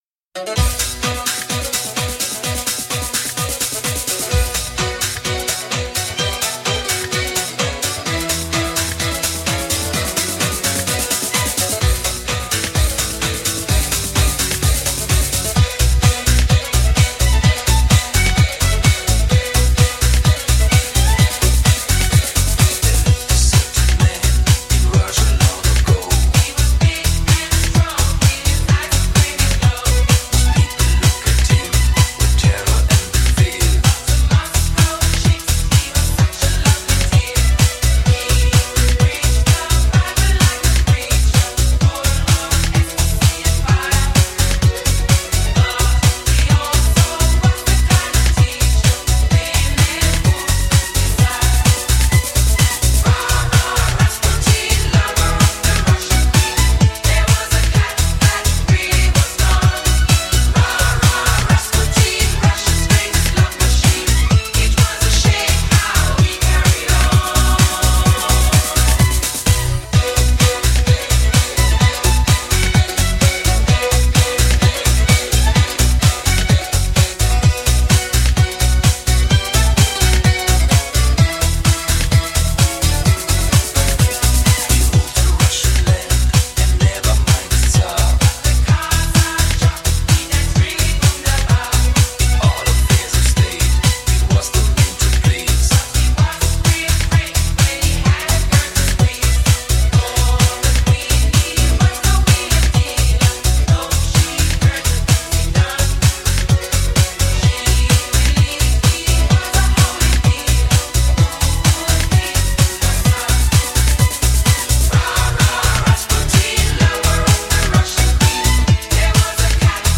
真正适合车载空间使用之6.1SIMULATION360度环绕高临场CD。
首创国际SRS+WIZOR全方位360环绕HI-FI AUTO SOUND 专业天碟。